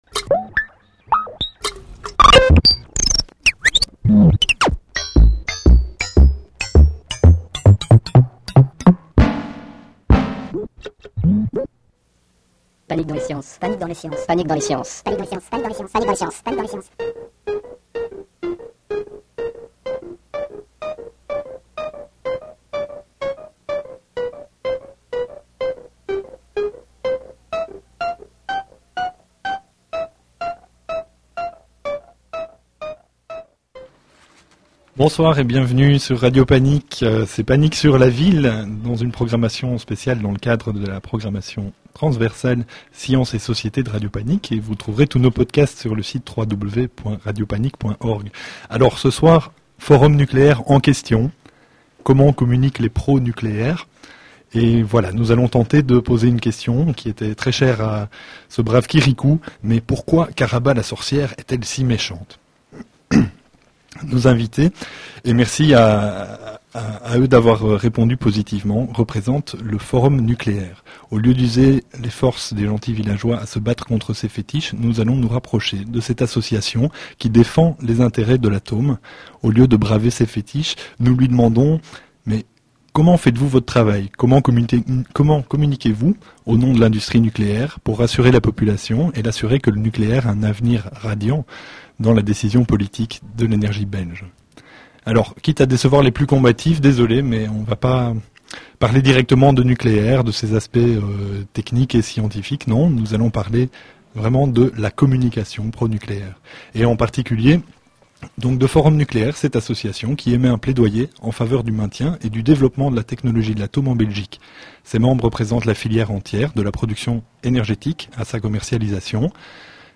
Panik sur la Ville invite Forum-Nucléaire, une association très controversée.